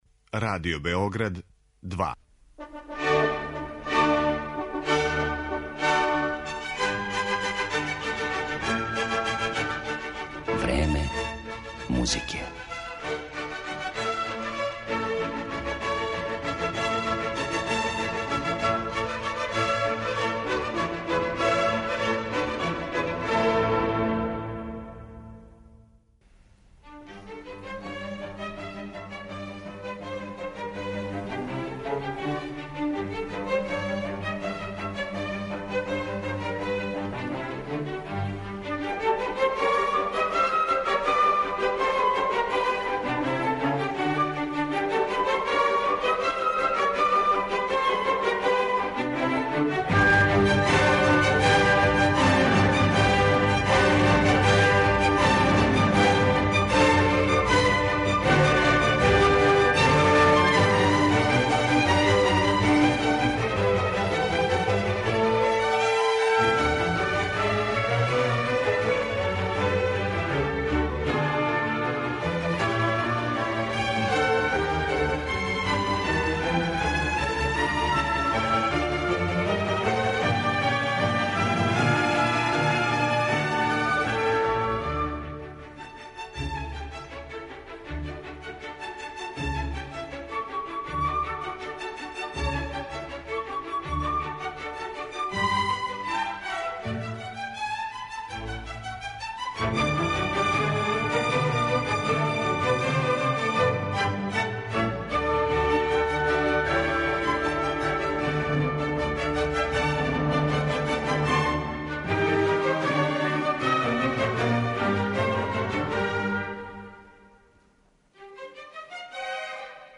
Данашња емисија посвећена је овом уметнику изузетне техничке спремности, кога су његове интензивне и дубоко доживљене интерпретације учиниле једним од највећих имена оркестарског дириговања у XX веку. Слушаћете дела Хајдна, Чајковског, Вагнера, Бартока и Мусоргског.